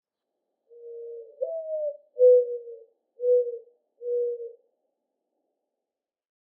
Tourterelle triste
La tourterelle triste semble se lamenter. Son «hou-ah hou hou hou» est facile à reconnaître avec ses notes plus basses à la fin.